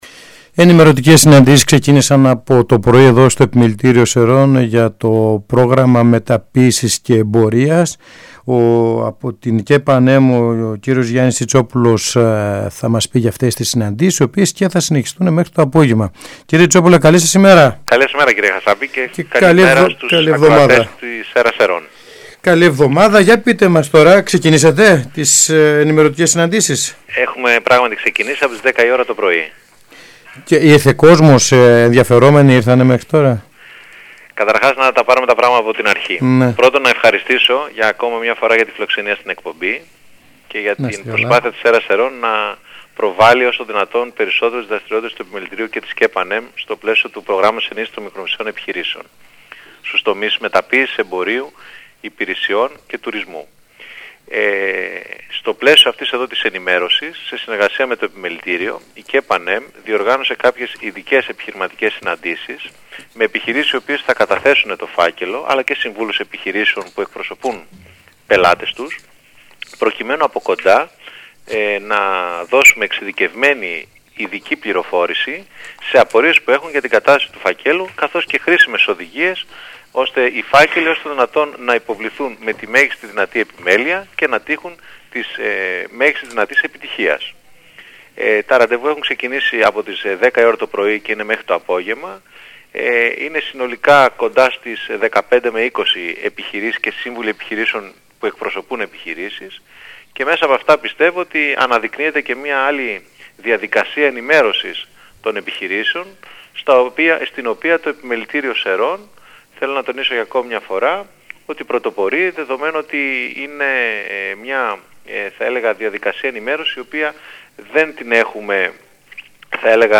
Συνέντευξη στο Ρ/Σ ''ΕΡΑ ΣΕΡΡΩΝ''